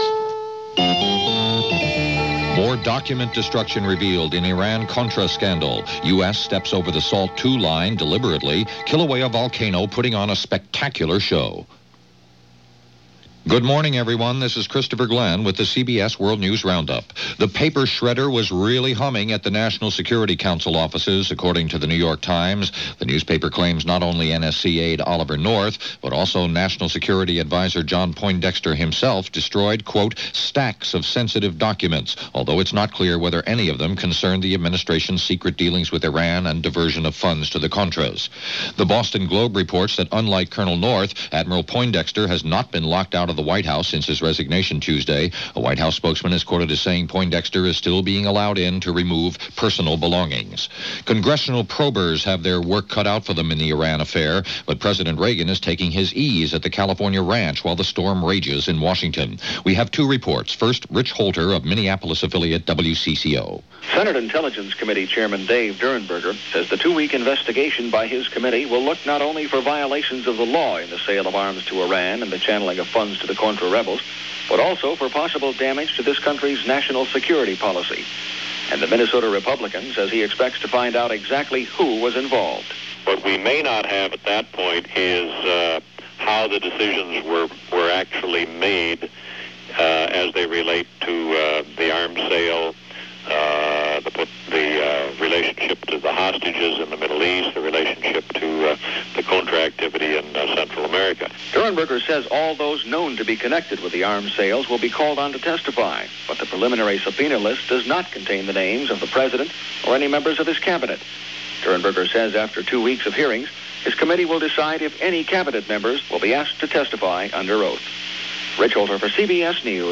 November 29, 1986 – CBS World News Roundup – Gordon Skene Sound Collection –